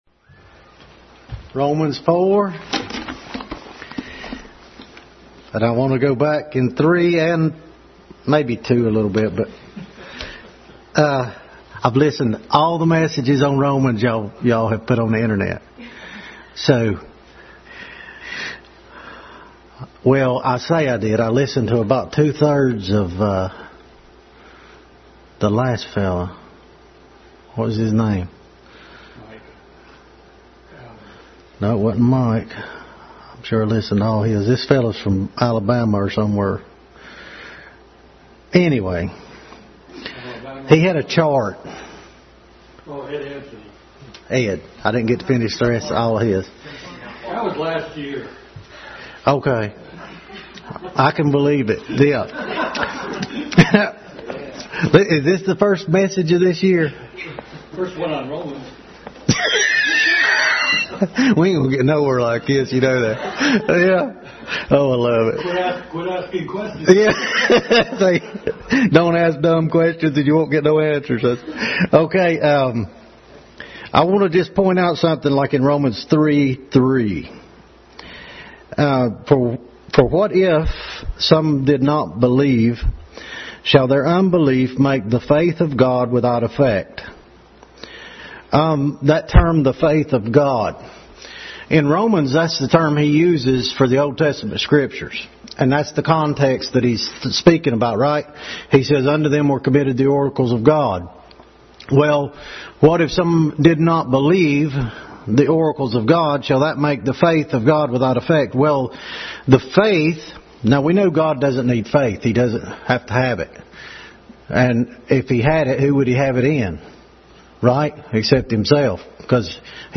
Adult Sunday School Class continued study in Romans.